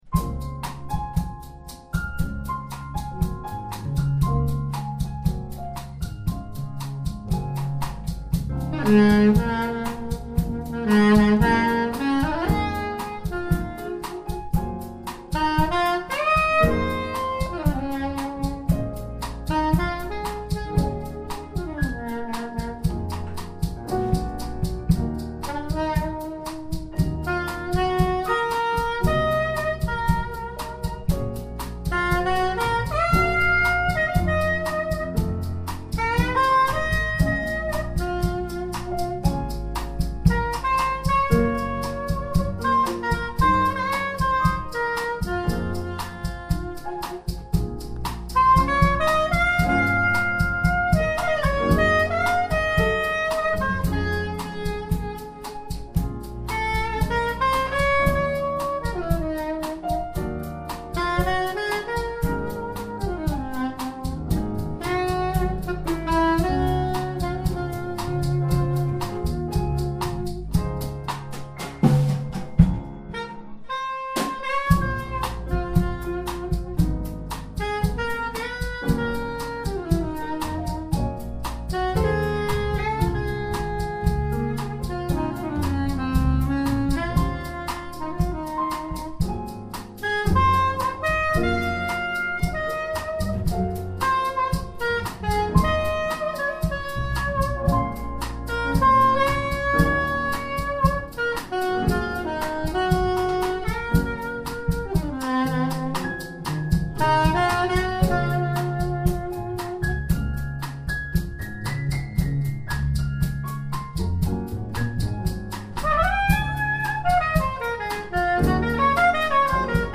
soprano saxophone
Jazz Quartet
and has now sent an MP3 of his quartet at one of their rehearsals.
like the pastoral sounds of Elgar - nice voicings and, at the same time, some progressions related to a jazz idiom.